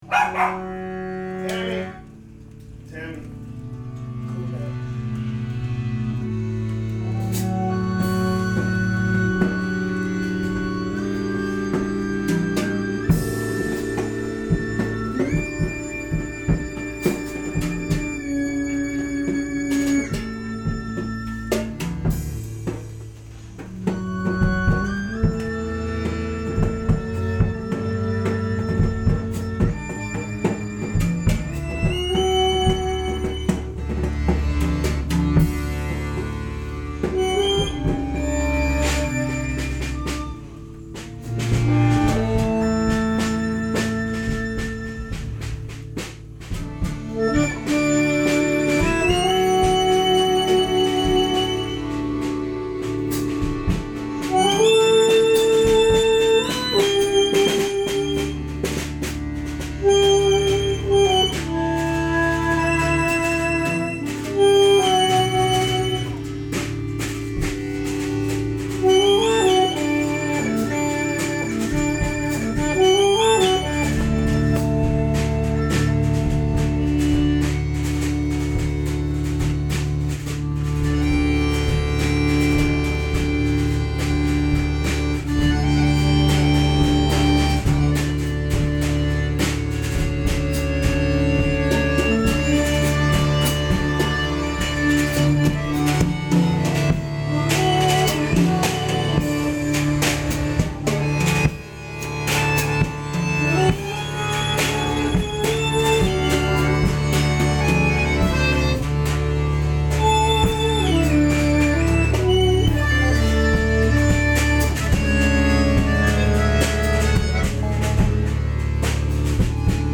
Recorded live
alto saxophone
accordion
drums
Binaural Stereo (Tascam DR100)